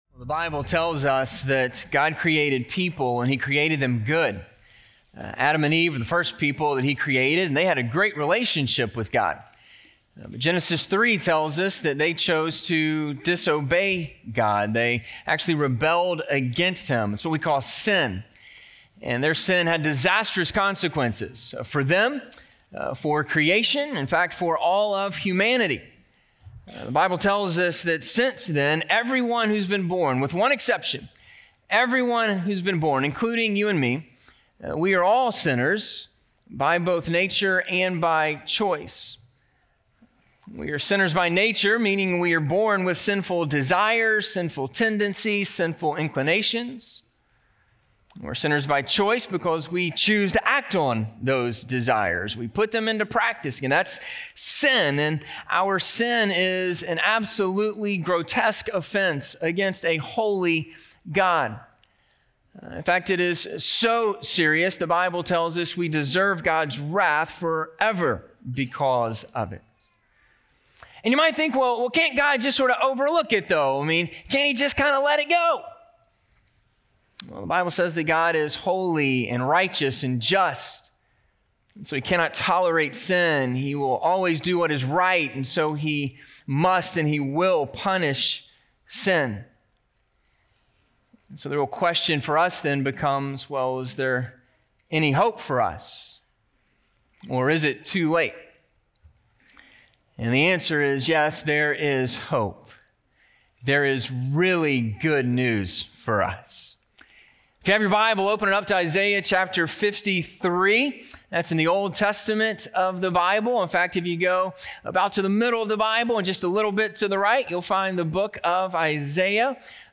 Pierced for Our Transgressions - Sermon - Ingleside Baptist Church